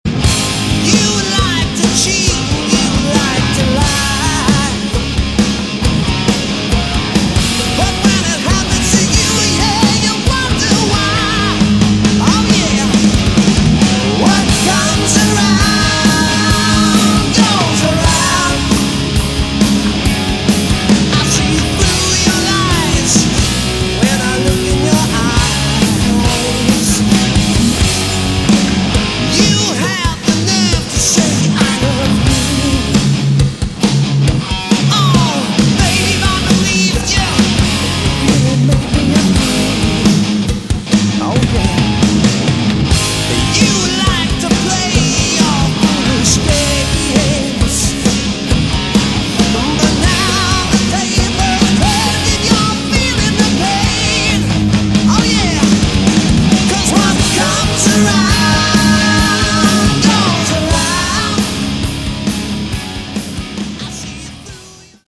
Category: Sleaze Glam / Hard Rock